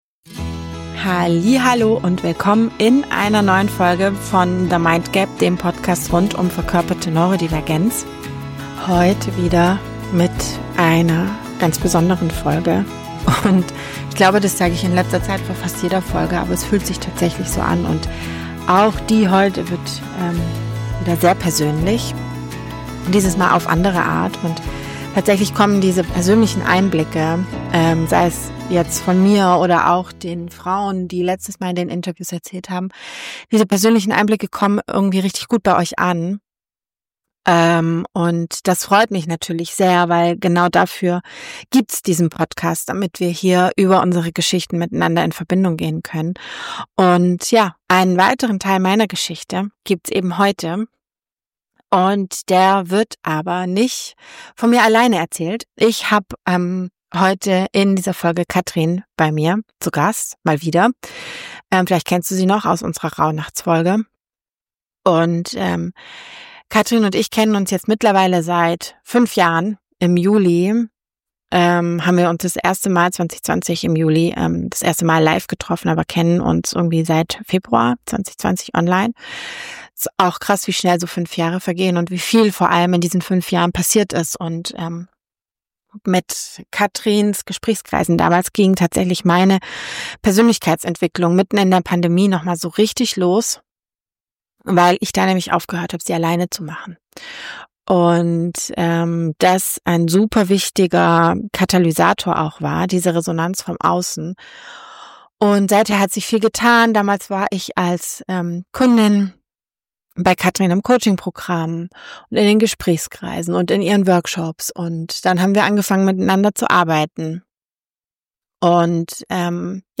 Wir unterhalten uns darüber, …wie sich Freundschaften verändern, wenn Masken fallen.